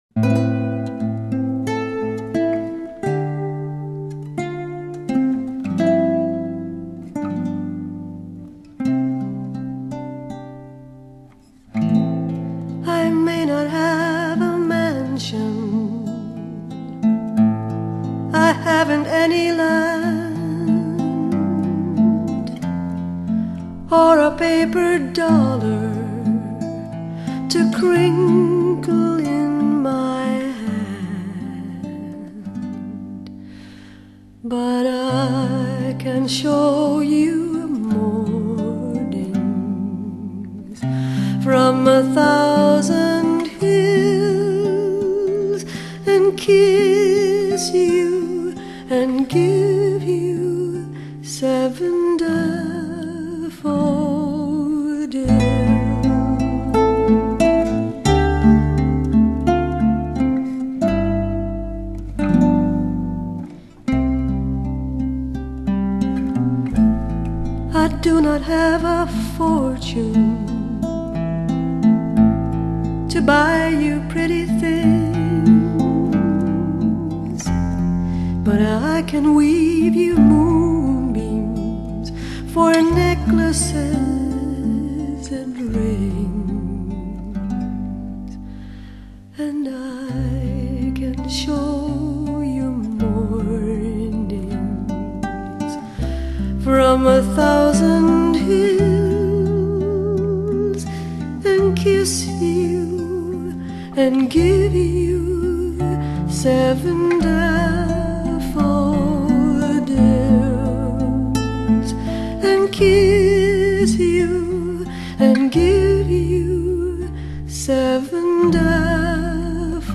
收錄耳熟能詳的爵士、民謠及福音歌曲。